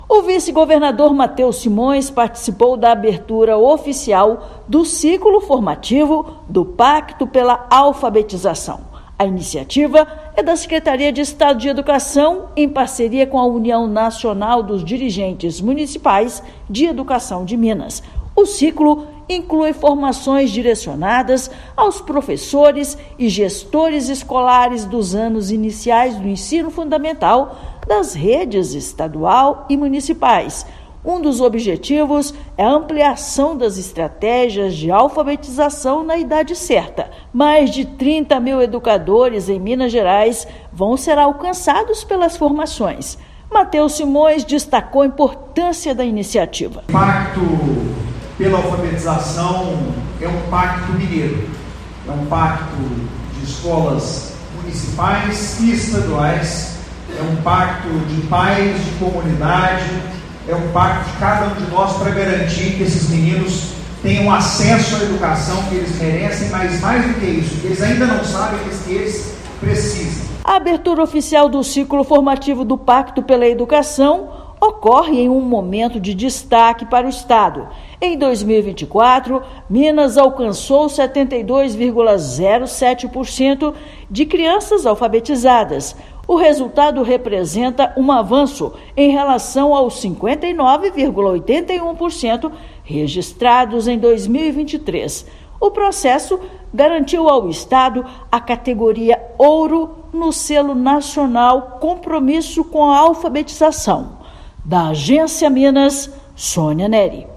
Evento reuniu educadores e autoridades para fortalecer ações de alfabetização; em 2024, estado se tornou destaque nacional ao alcançar mais de 72% de crianças alfabetizadas. Ouça matéria de rádio.